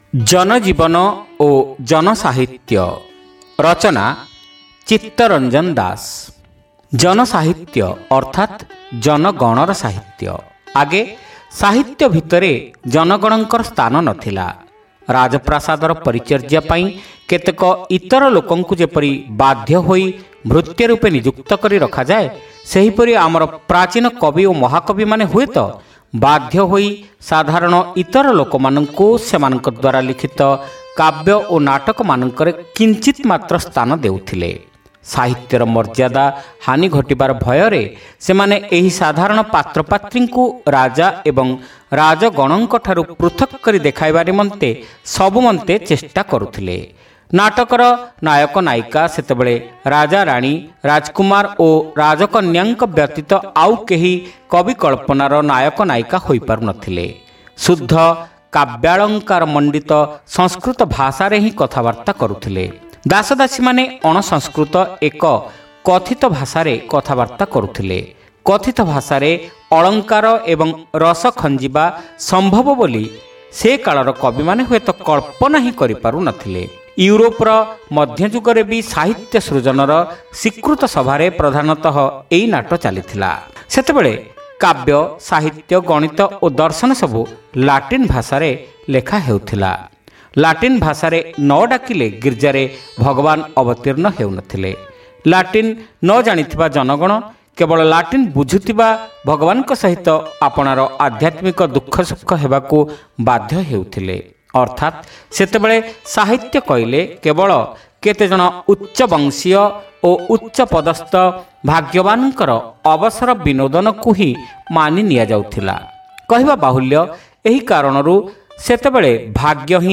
Audio Story : Jana Jeevana o Jana Sahitya